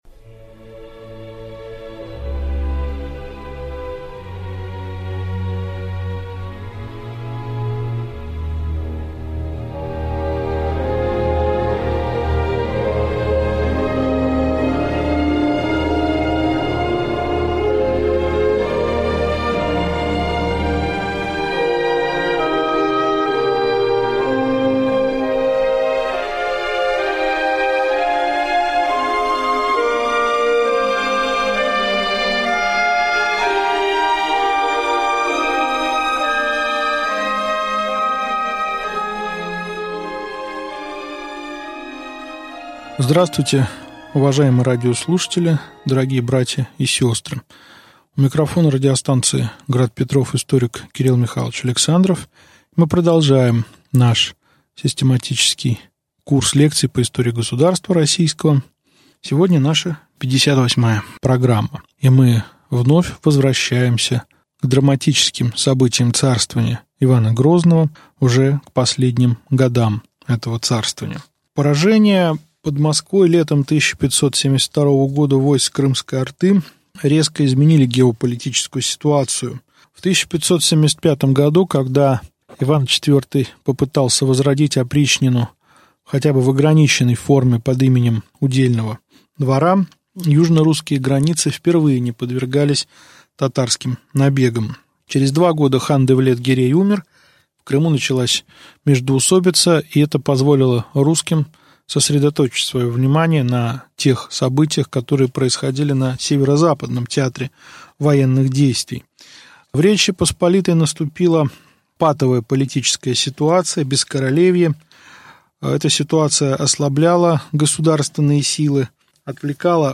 Аудиокнига Лекция 58. Ливонская война. Ее результаты | Библиотека аудиокниг